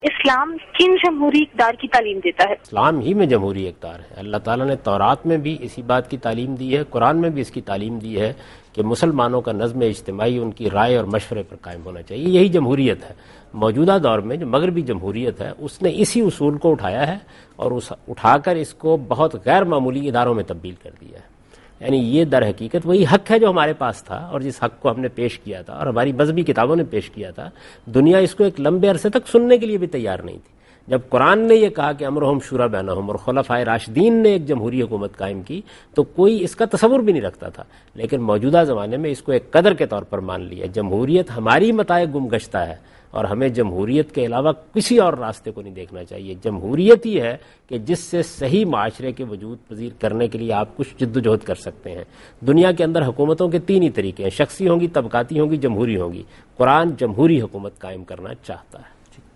TV Programs
Answer to a Question by Javed Ahmad Ghamidi during a talk show "Deen o Danish" on Duny News TV